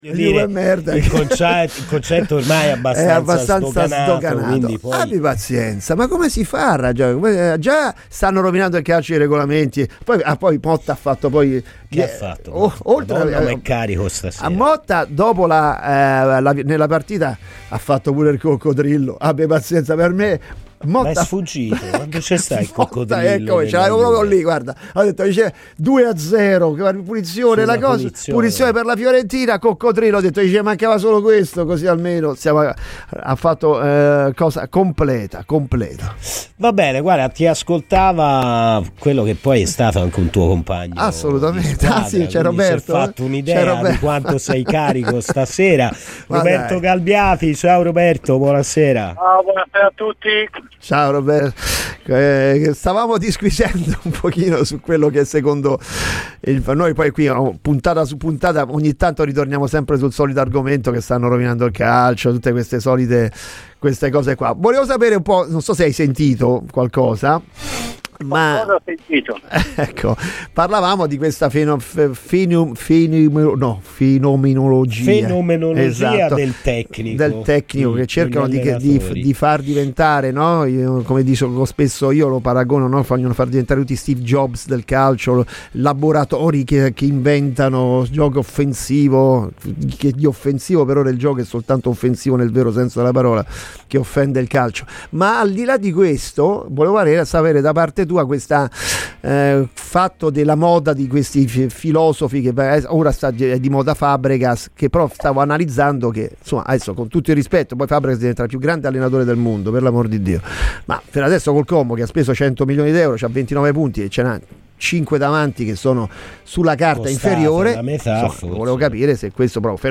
è intervenuto ai microfoni di Radio FirenzeViola durante la trasmissione "Colpi d'Ala"